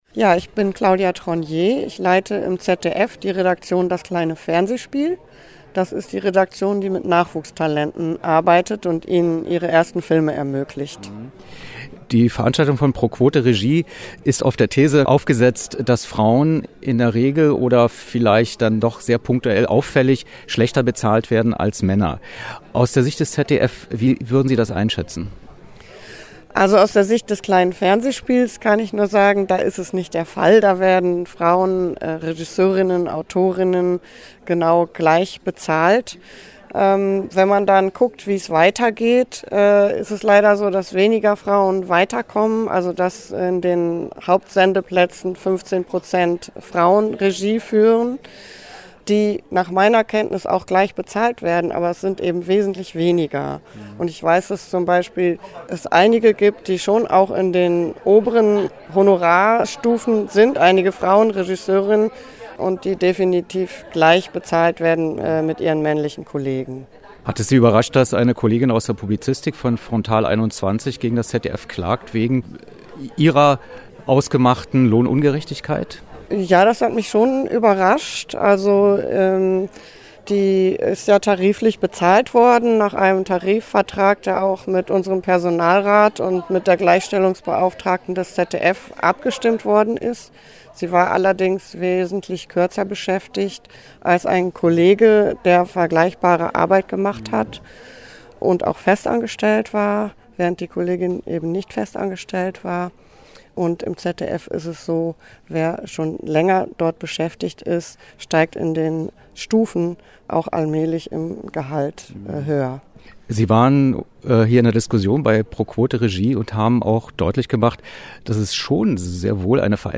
Was: Interview zur Situation der Gleichstellung und Lohngerechtigkeit beim ZDF
Wo: Berlin, Bundesministerium für Familie, Senioren, Frauen und Jugend, BMFSFJ, Glinkastraße 24